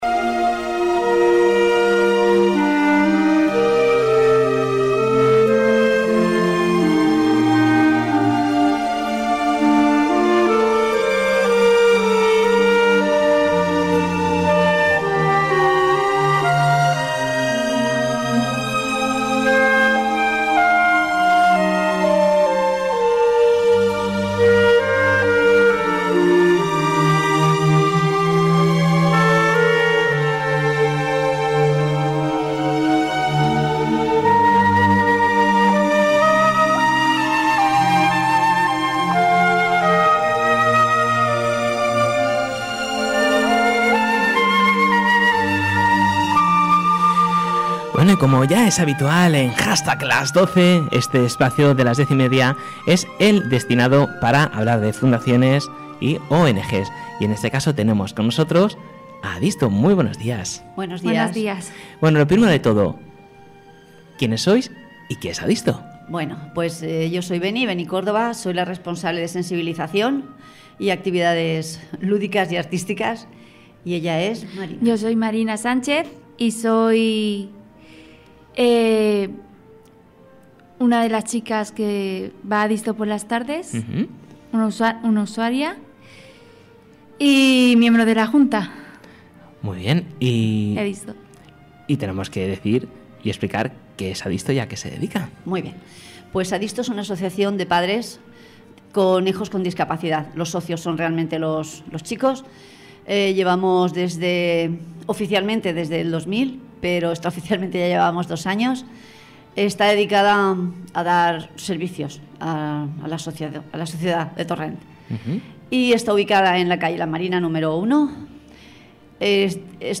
Ayer estuvimos en los estudios de MediteRadio Fm Valencia inaugurando la temporada de le programa «Hastag Las Doce.»